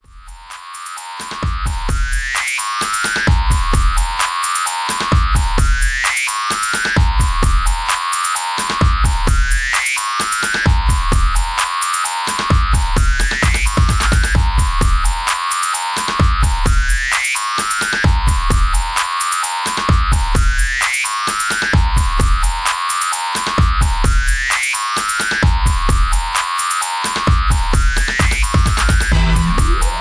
Rowdy Club Banger